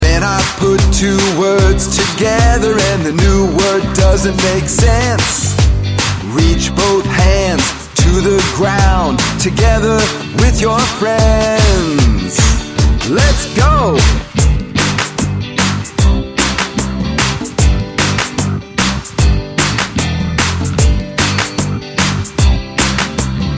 A fun movement song teaching compound words!
Listen to the open version of this song.